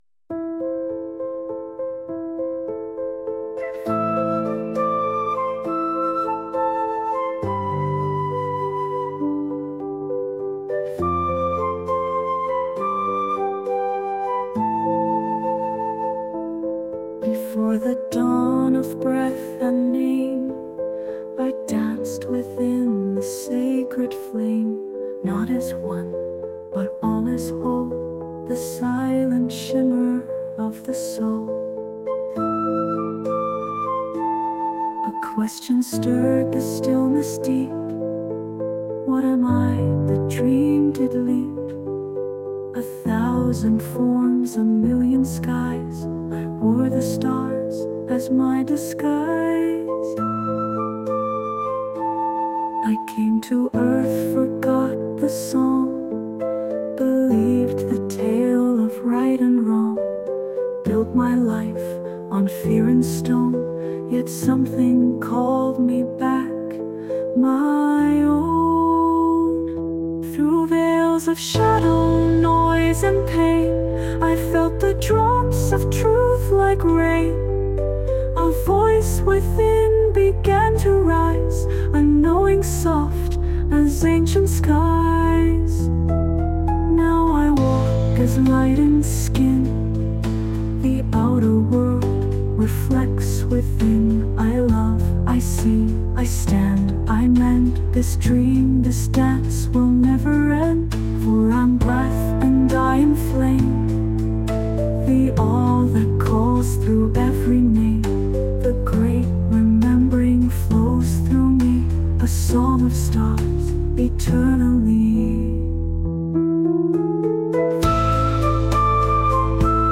Song: Whispers of Infinity, the Great Remembering